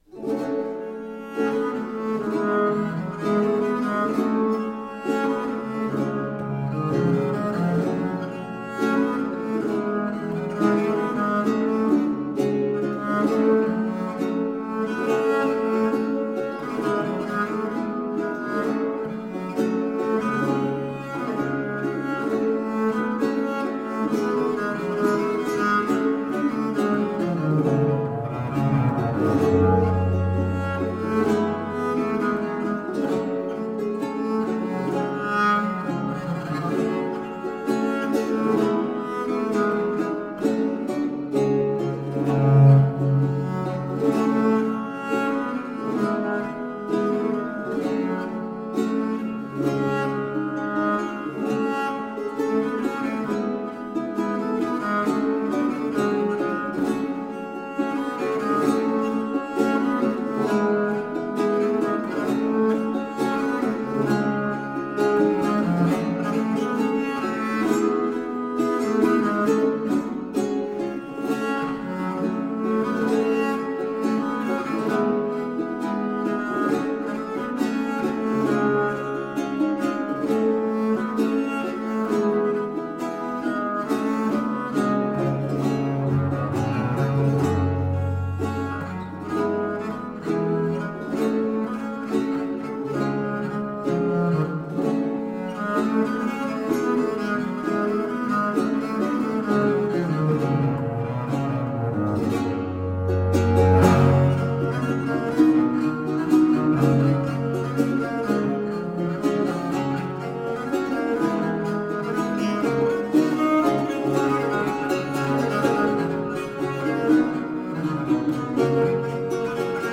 Rare and extraordinary music of the baroque.
using period instruments